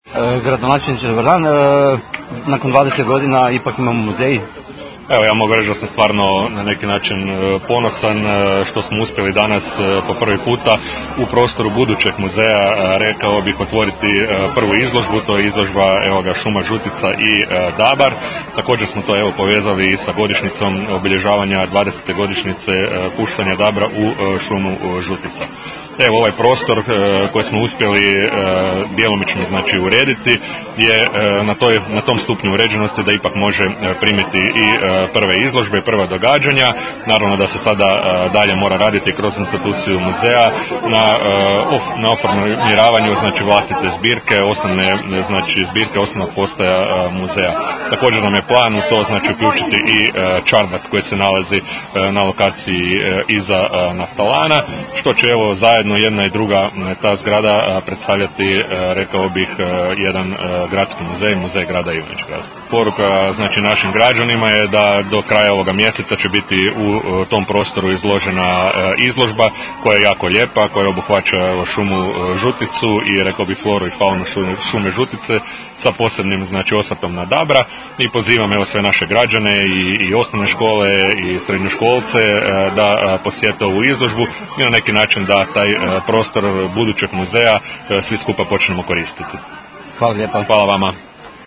RAZGOVOR S GRADONAČELNIKOM JAVOROM BOJANOM LEŠOM